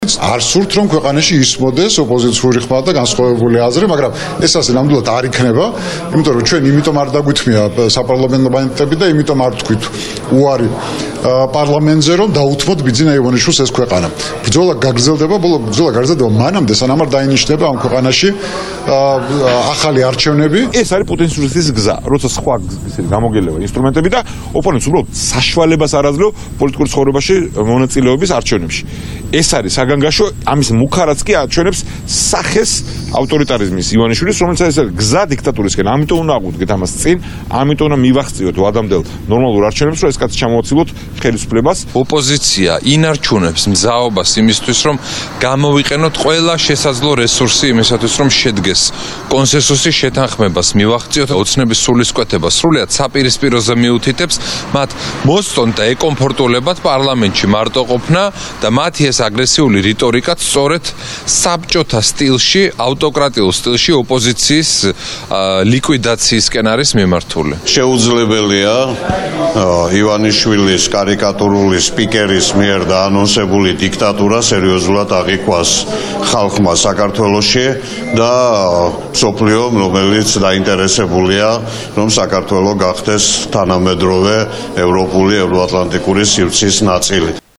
მოვისმინოთ ზაალ უდუმაშვილის, გიგა ბოკერიას, საბა ბუაძის,დავით ბერძენიშვილის განცხადებები